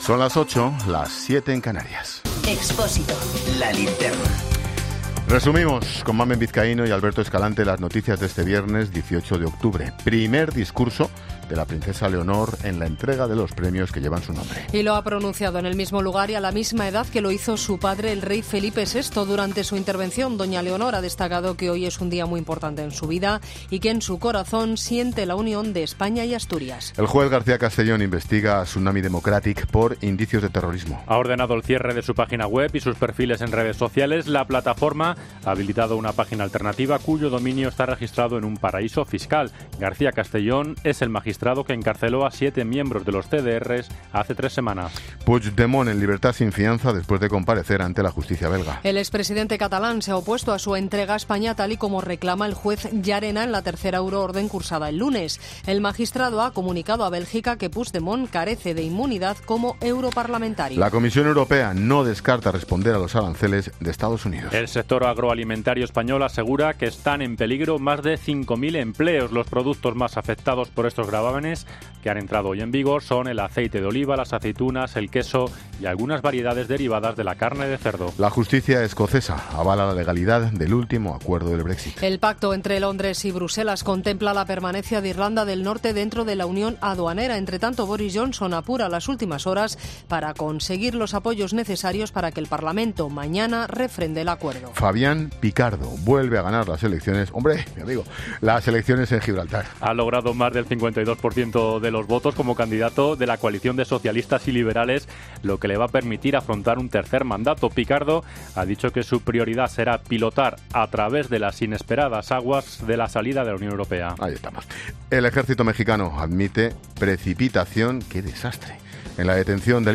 AUDIO: Boletín de noticias de COPE del 18 de octubre de 2019 a las 20.00 horas